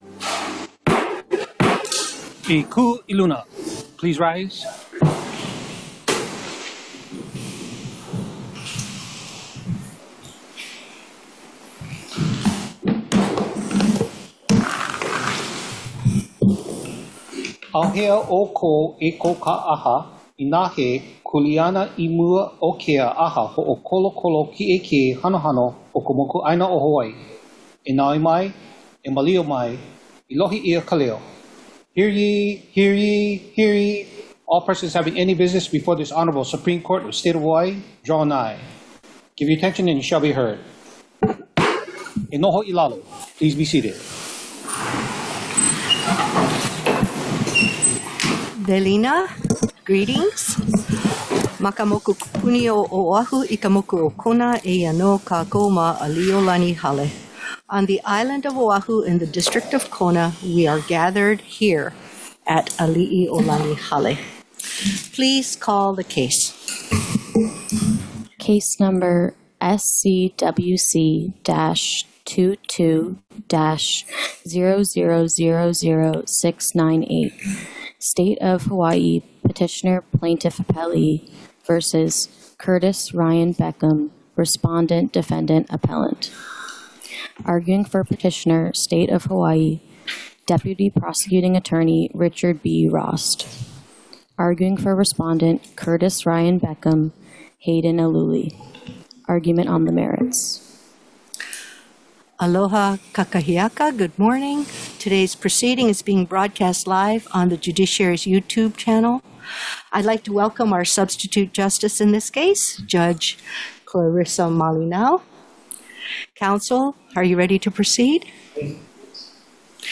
The above-captioned case has been set for oral argument on the merits at: Supreme Court Courtroom Ali‘iōlani Hale, 2nd Floor 417 South King Street Honolulu, HI 96813